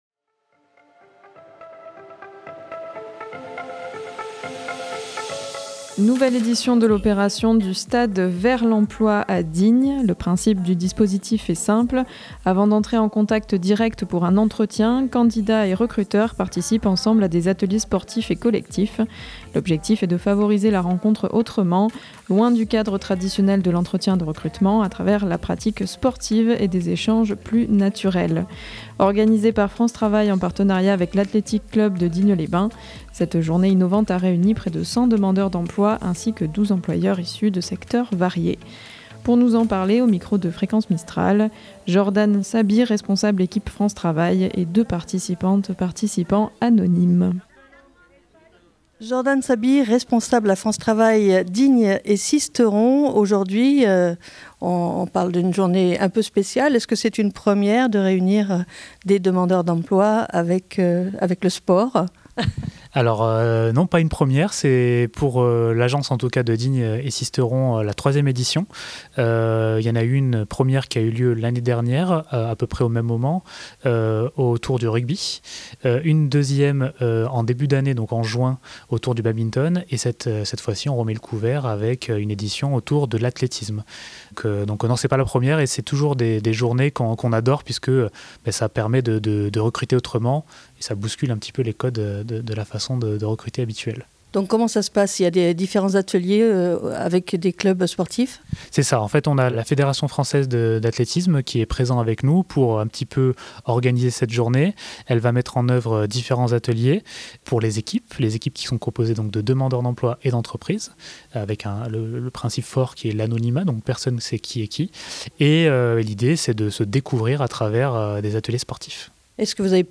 et deux participant·e·s anonymes.